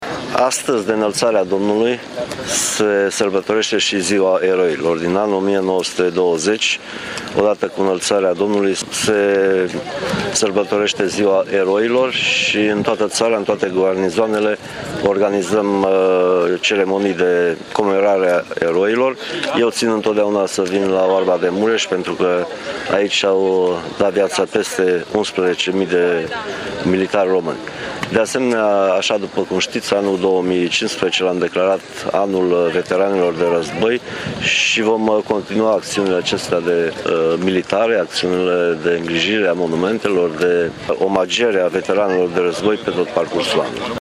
La eveniment a participat și ministrul Apărării Naționale, Mircea Dușa.